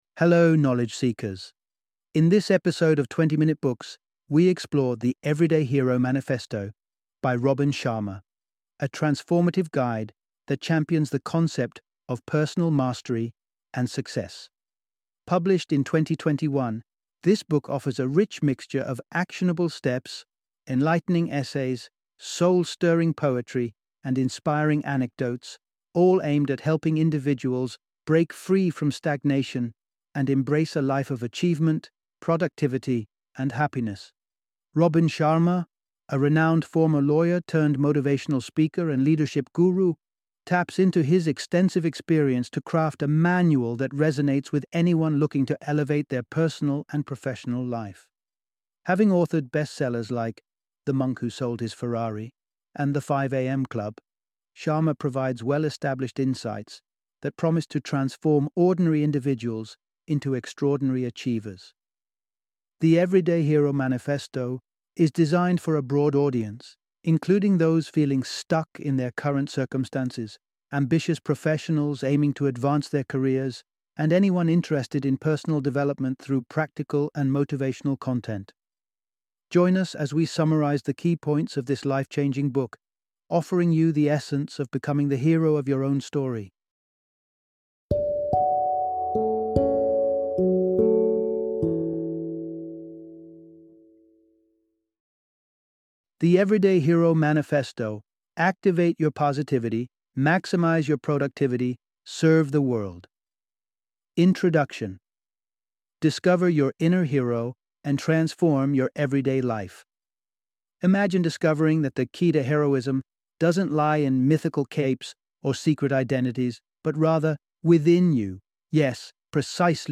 The Everyday Hero Manifesto - Audiobook Summary